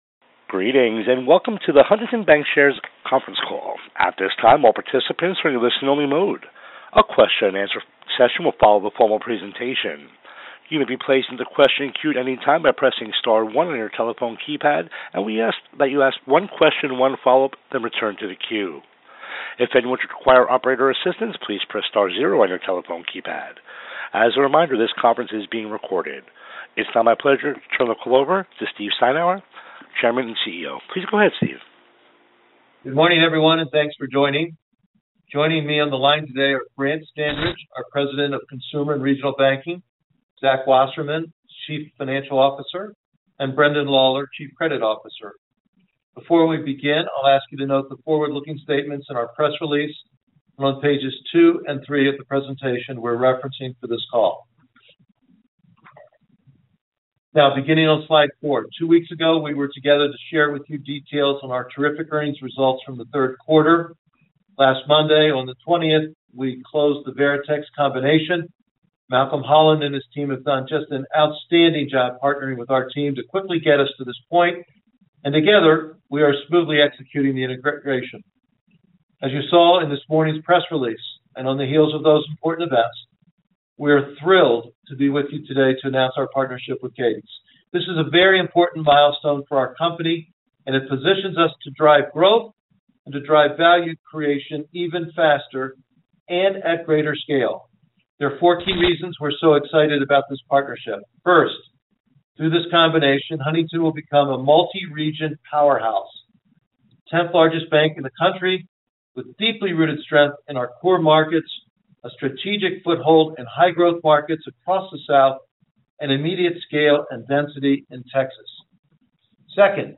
Cadence Bank Acquisition Conference Call